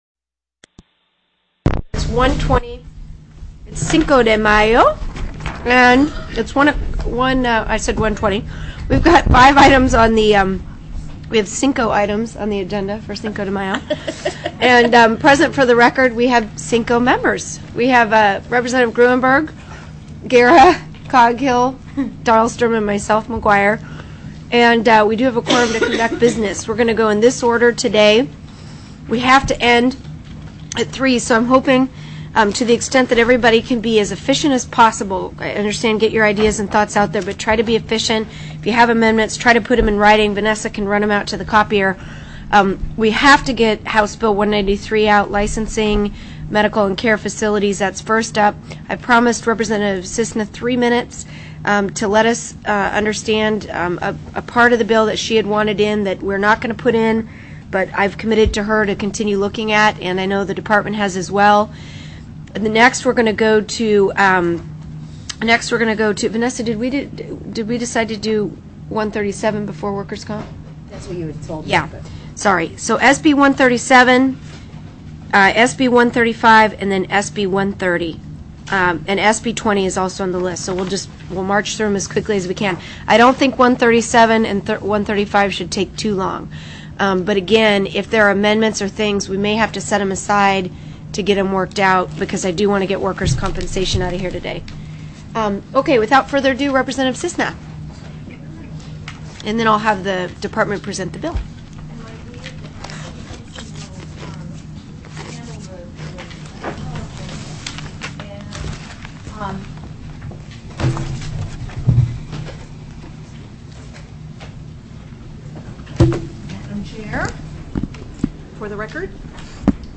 05/05/2005 01:00 PM House JUDICIARY
HOUSE JUDICIARY STANDING COMMITTEE